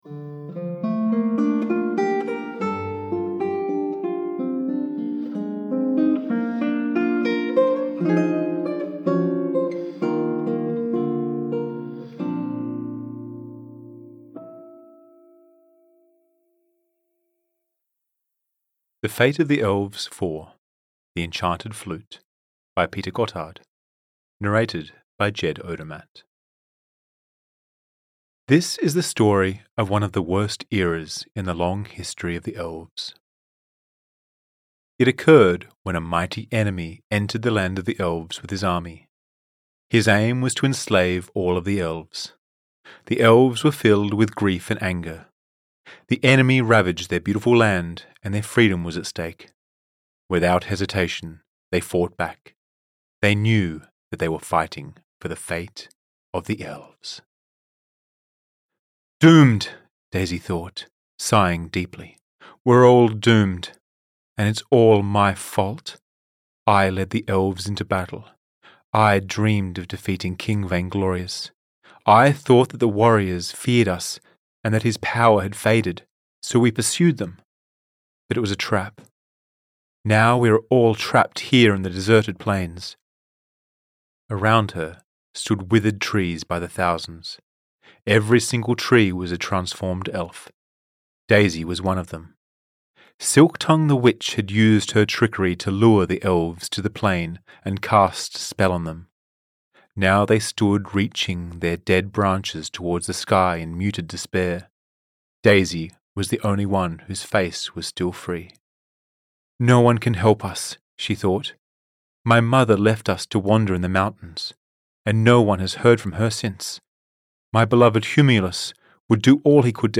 The Fate of the Elves 4: The Enchanted Flute (EN) audiokniha
Ukázka z knihy